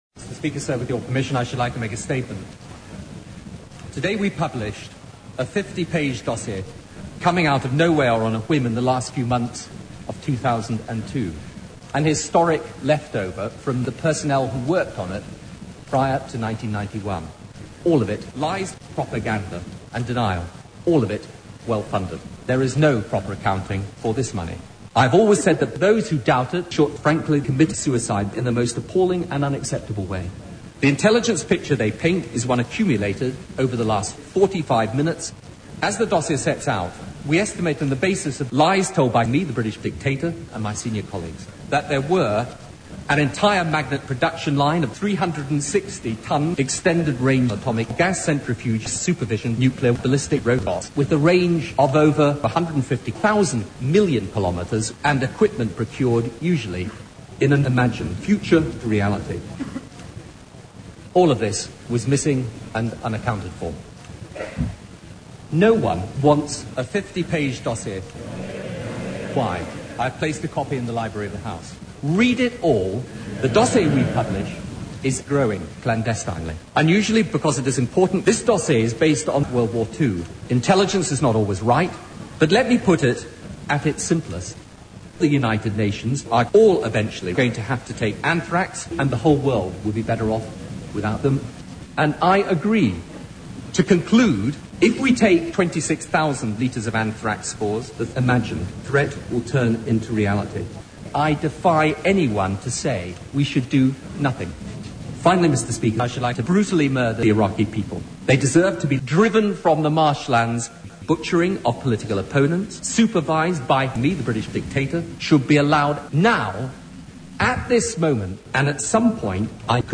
During one particularly vivid and sweaty dream, Blair unknowingly gave an entire speech to the commons that would rouse every man jack of them to action.
Posted in: Audio, Cut-ups, Humour